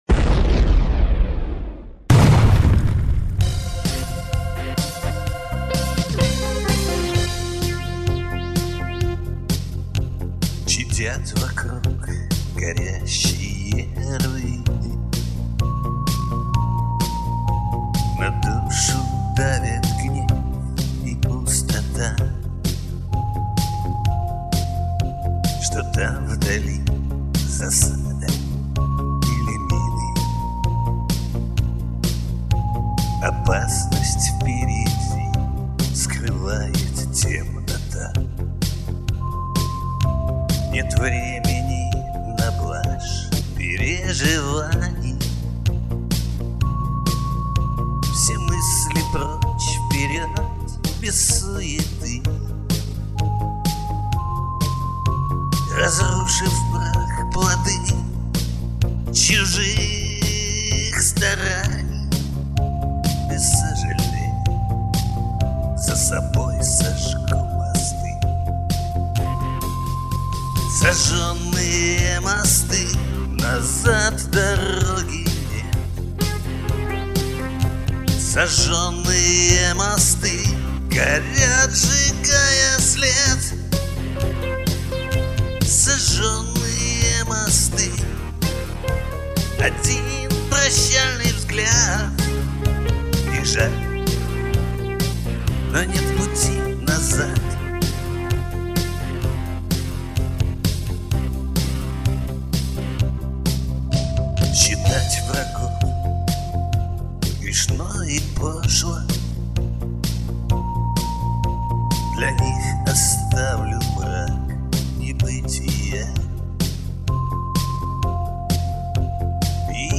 Жанр: Русский поп-шансон